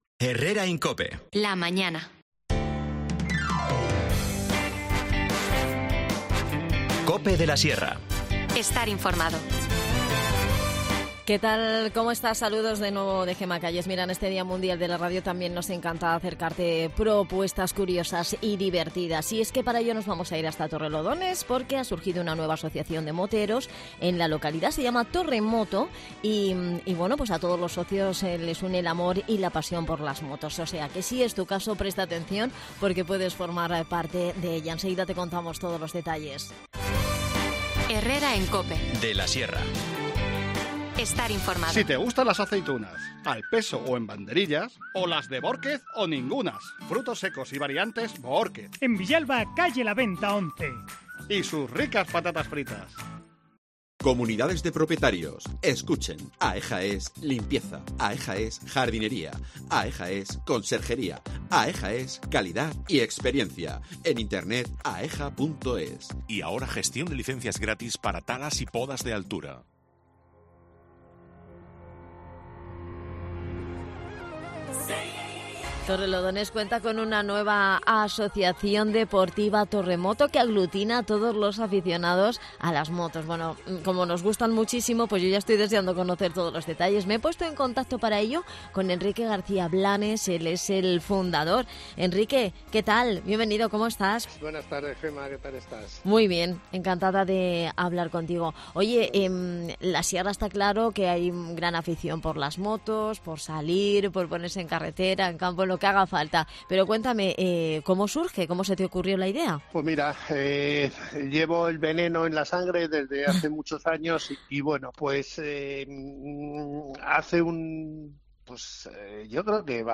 Charlamos